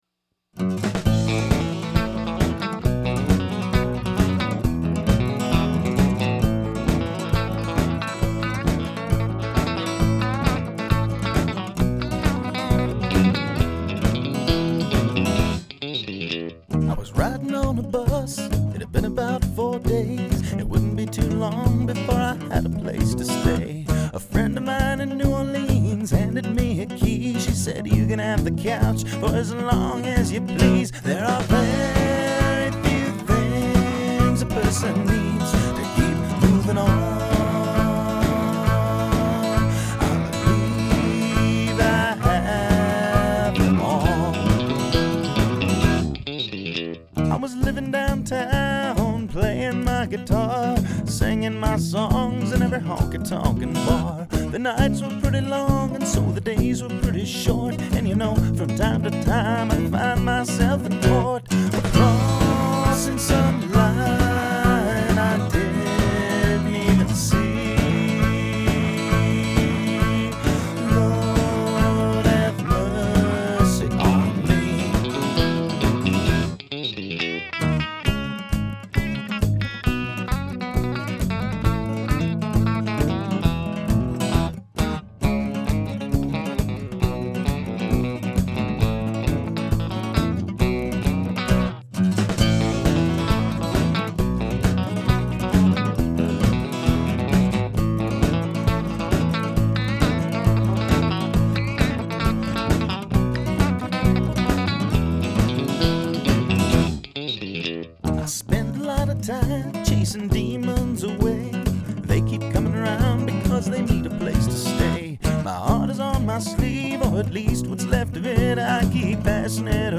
I'm taking my time with the mix so it is rather dry. In fact........ drums-dry bass- dry acoustic-dry harp- dry guitar1- comp., EQ, amp sim. guitar2- comp., EQ, amp sim. vocal- comp., EQ harmony- comp., EQ